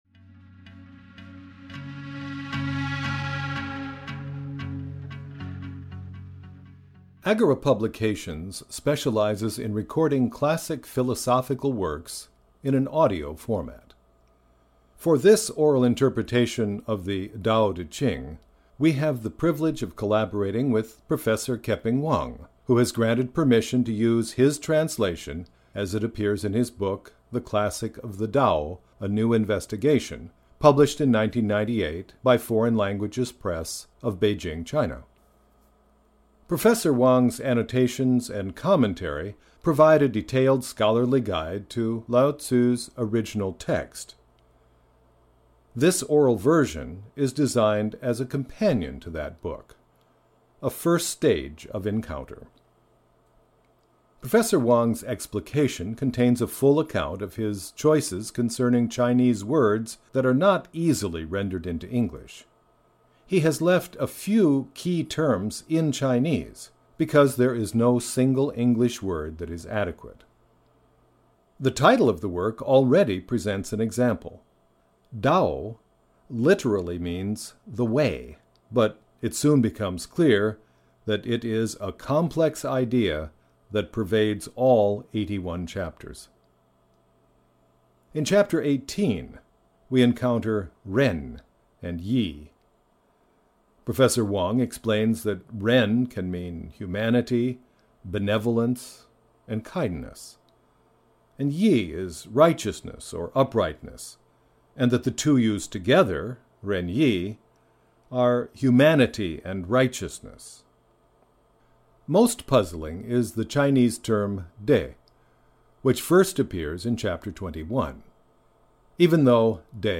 Lao Zi’s Dao De Jing (EN) audiokniha
Ukázka z knihy